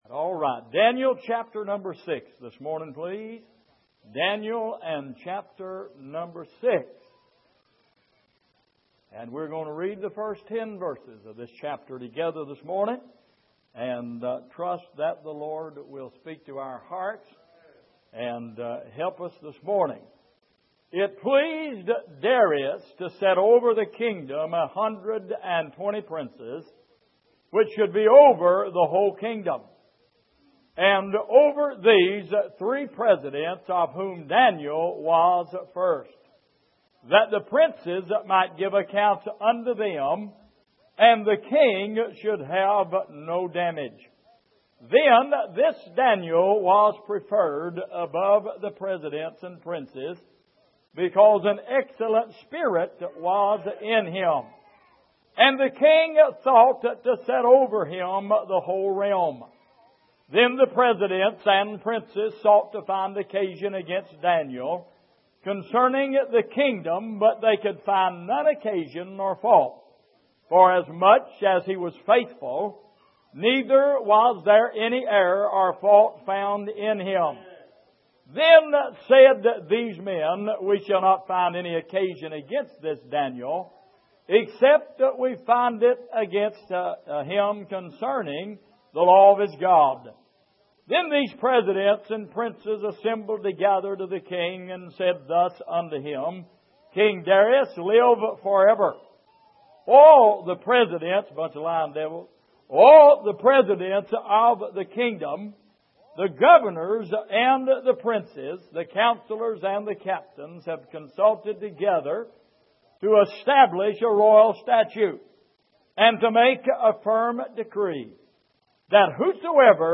Passage: Daniel 6:1-10 Service: Sunday Morning